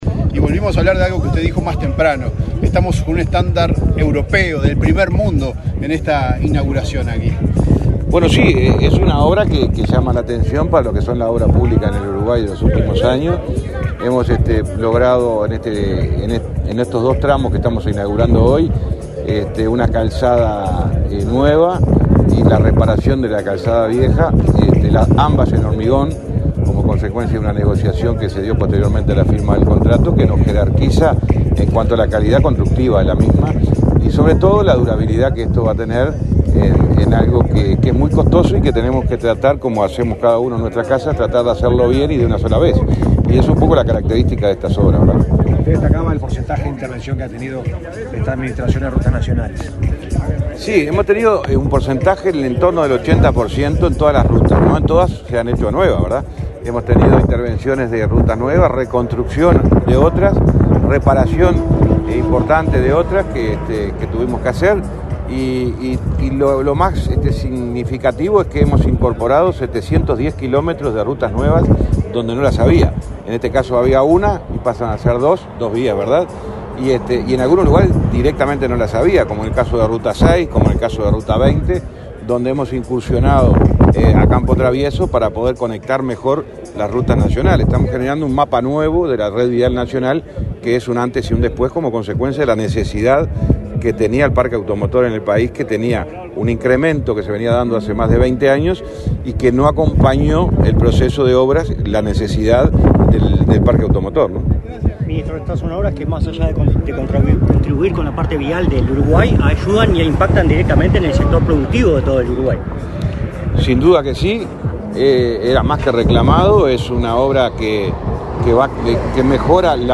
Declaraciones a la prensa del ministro de Transporte y Obras Públicas, José Luis Falero
Declaraciones a la prensa del ministro de Transporte y Obras Públicas, José Luis Falero 20/11/2024 Compartir Facebook X Copiar enlace WhatsApp LinkedIn El ministro de Transporte y Obras Públicas, José Luis Falero, participó, este 20 de noviembre, en el acto de habilitación y apertura al tránsito de 54.5 kilómetros de doble vía de ruta 5, en el departamento de Florida. En la oportunidad, el ministro realizó declaraciones a la prensa.